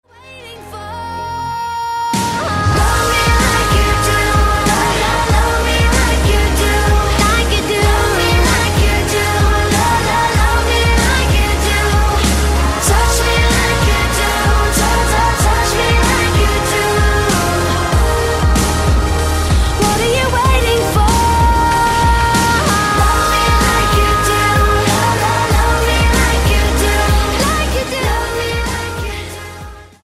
• Pop Ringtones
soft vocals